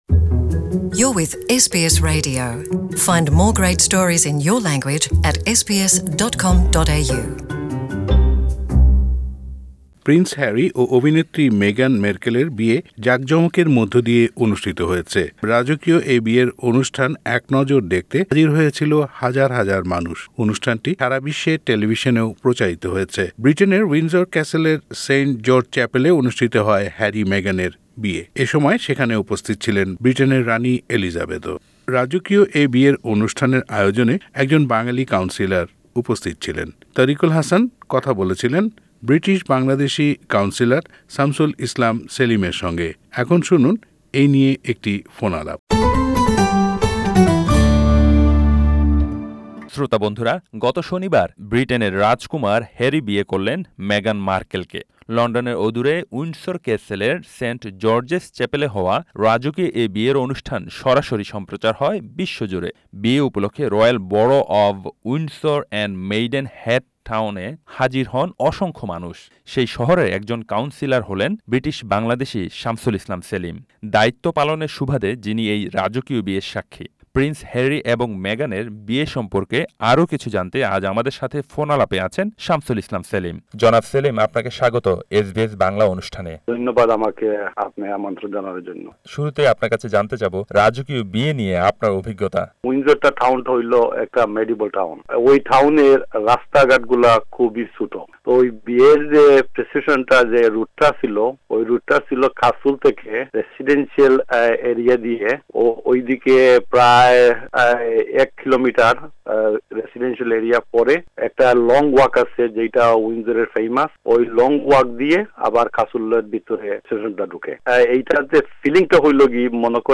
British Bangladeshi Shamsul Islam Shelim is one of the councillors and was involved in the ceremony. SBS Bangla spoke with the Mr. Shelim about the royal wedding.